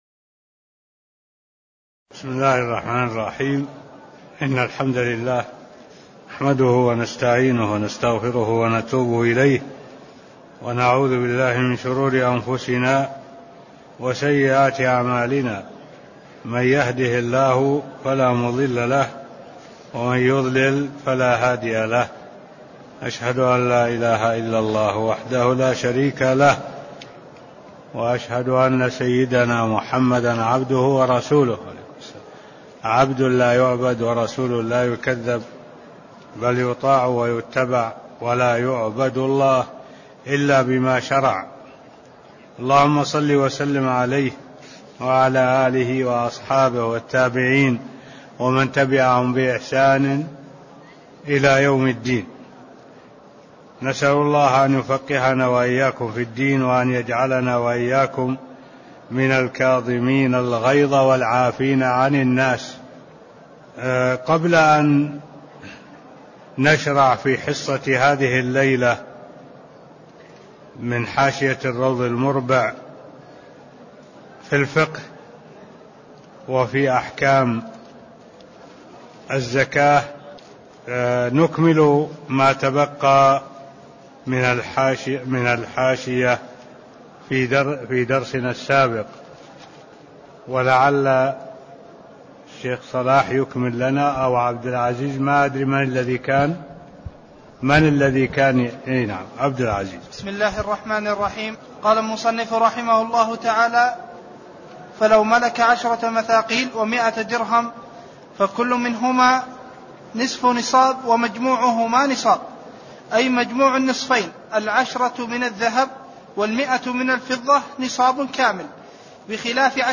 تاريخ النشر ٦ صفر ١٤٢٧ هـ المكان: المسجد النبوي الشيخ: معالي الشيخ الدكتور صالح بن عبد الله العبود معالي الشيخ الدكتور صالح بن عبد الله العبود باب زكاة النقدين (002)